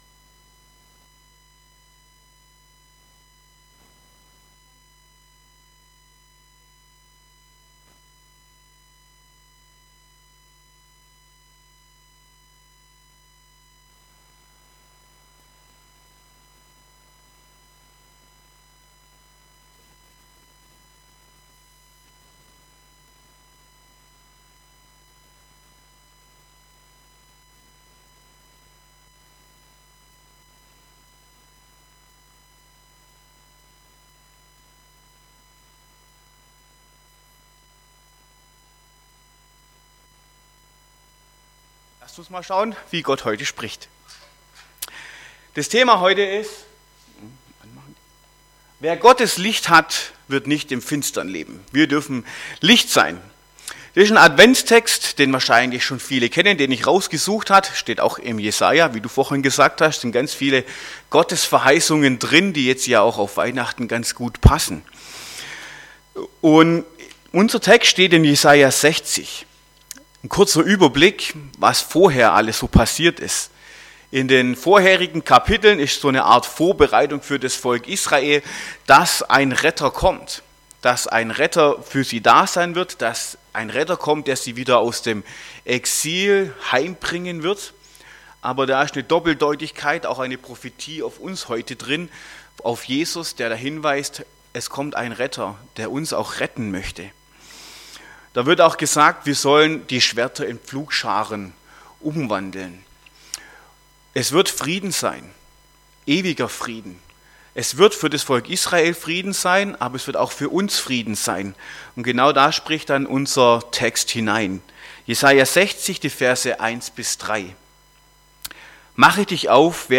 Gottesdienst :: Liebenzeller Gemeinde Stuttgart